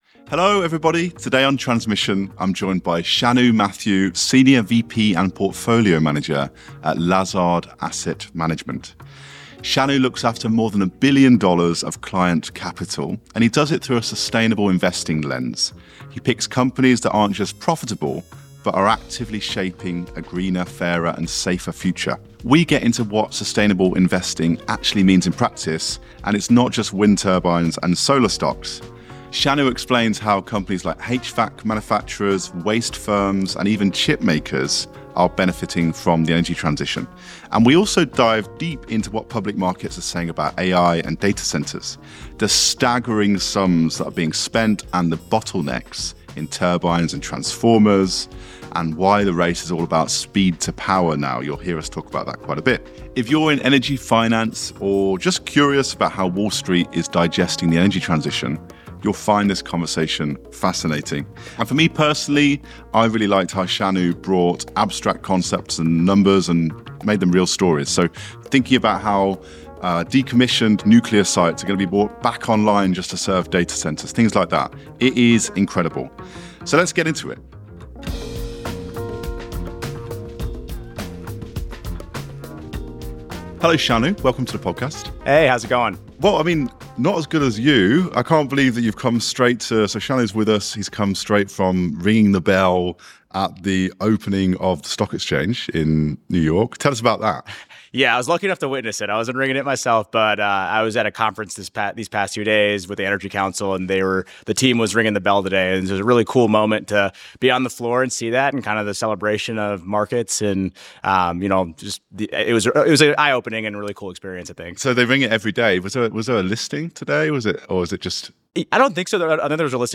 Note: Our guest caught a slip of the tongue—at timestamps 22:52, 23:08, and 23:21, they said "per square foot" but meant "per megawatt."